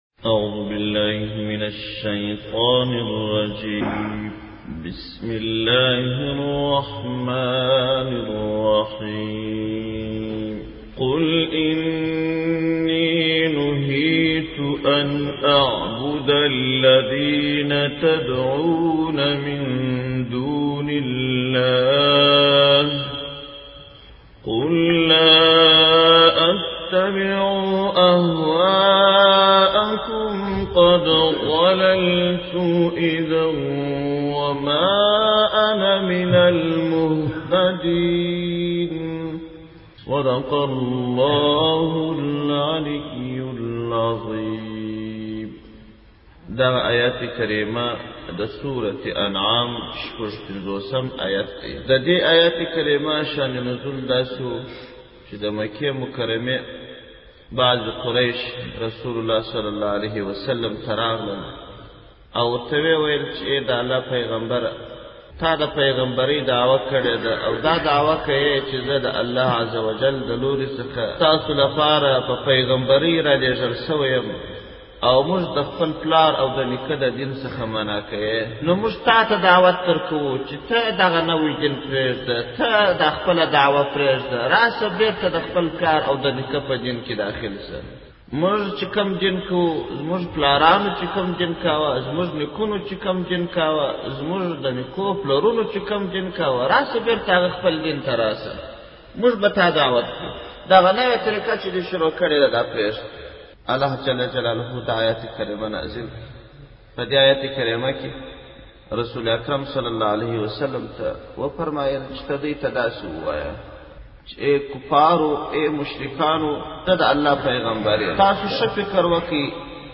دسمبر 19, 2016 تفسیرشریف, ږغیز تفسیر شریف 616 لیدنی